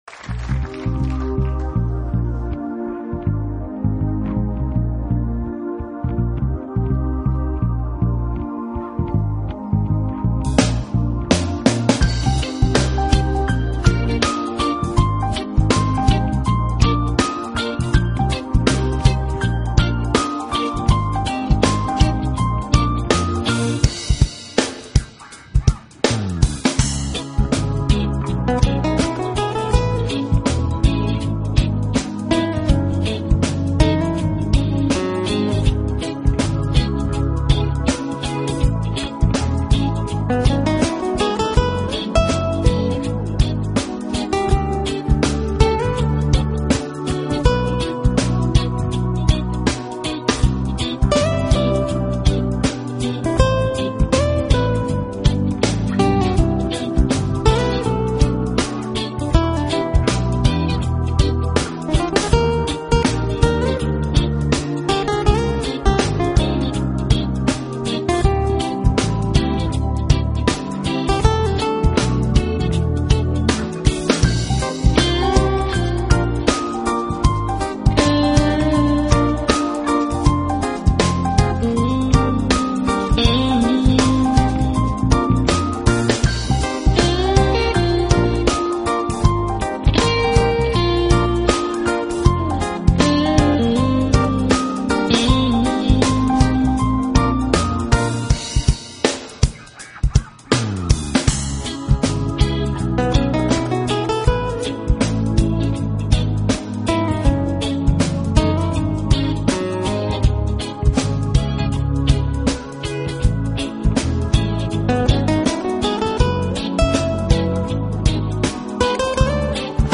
音乐类型：NewAge 新世纪
音乐风格：NEWAGE，Smooth Jazz，Contemporary，Instrumental